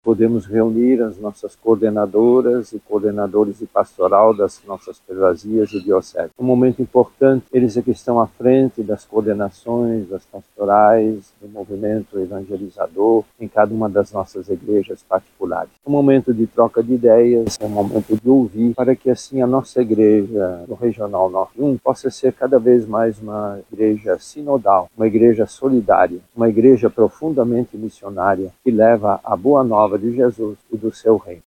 O presidente do Regional Norte 1 da Conferência Nacional dos Bispos do Brasil (CNBB), Cardeal Leonardo Steiner, destacou a relevância de ser uma Igreja sinodal, durante Encontro dos Coordenadores e Coordenadoras de Pastoral das Igrejas Locais do Regional Norte 1, realizado em Manaus.